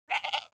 جلوه های صوتی
دانلود صدای مزرعه 21 از ساعد نیوز با لینک مستقیم و کیفیت بالا
برچسب: دانلود آهنگ های افکت صوتی طبیعت و محیط دانلود آلبوم صدای مزرعه روستایی از افکت صوتی طبیعت و محیط